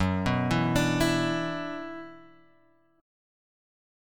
F#7sus4#5 chord {2 2 2 x 3 0} chord